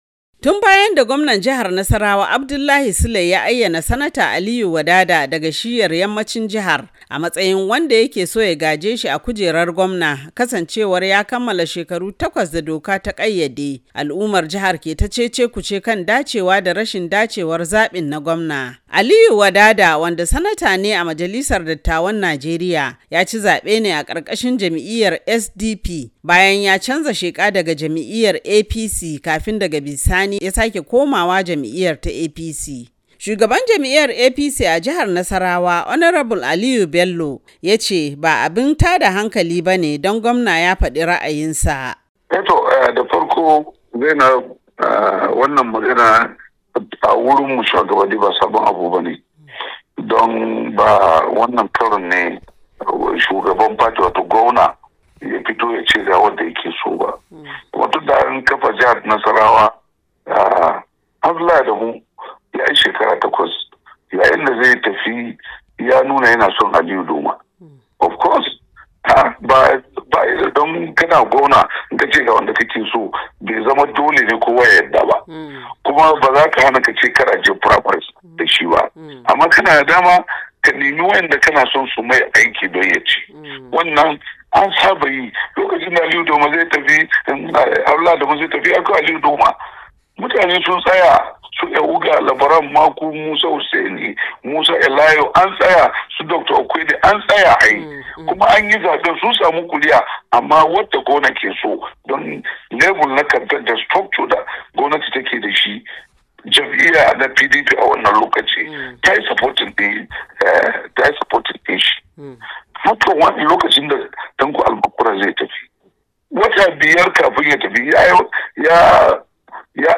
tana dauke da cikekken rahoton Daga Jos